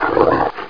ANU_WALK.mp3